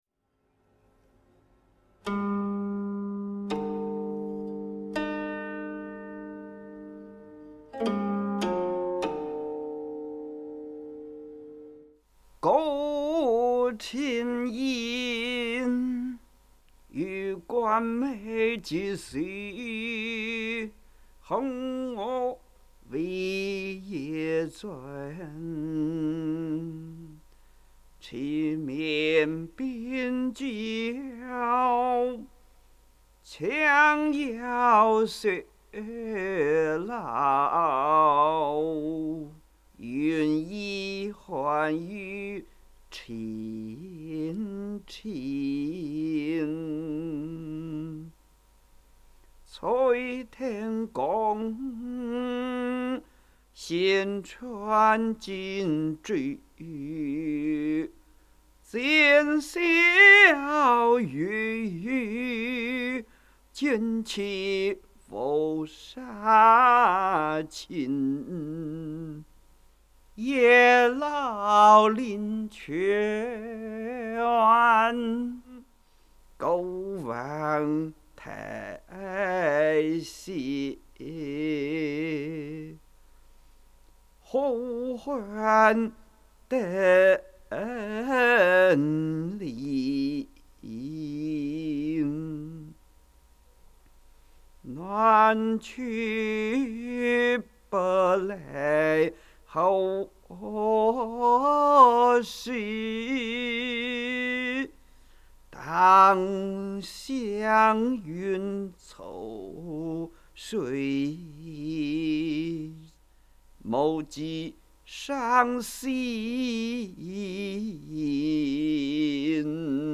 吟誦